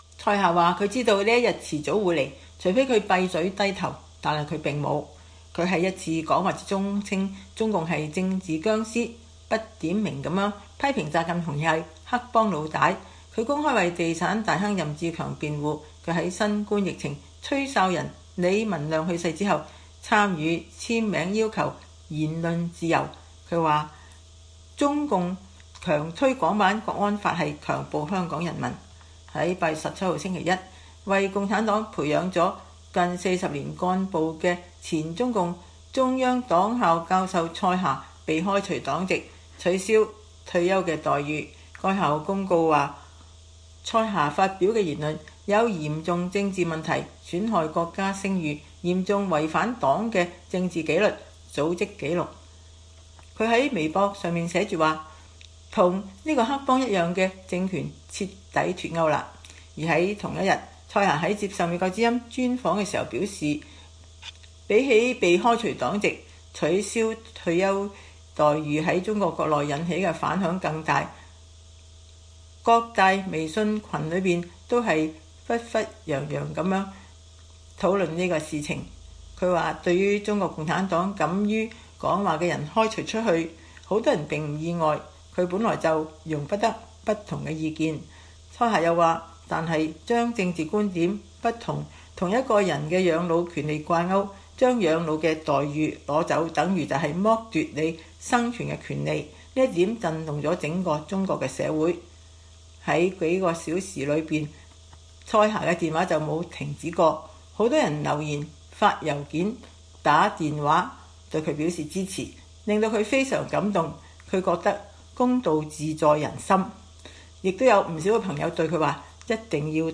被開除黨籍的蔡霞教授專訪： 共產黨的殭屍外殼必須被拋棄